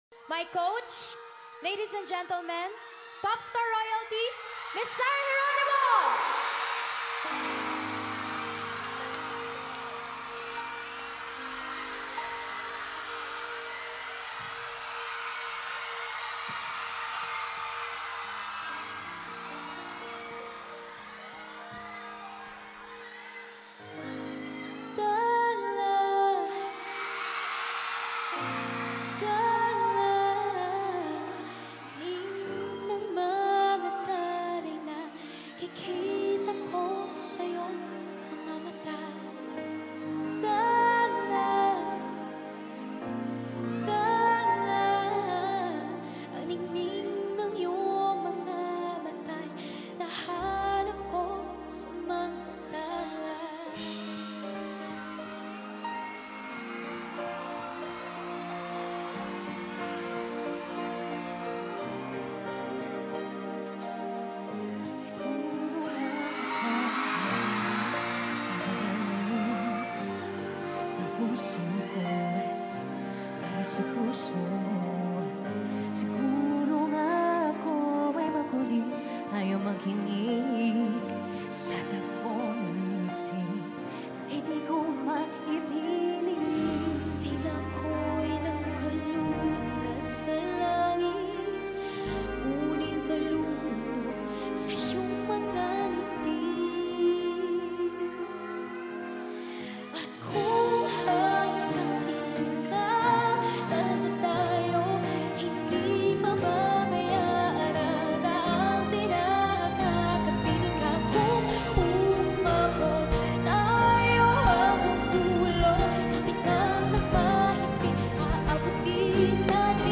Medley
Concert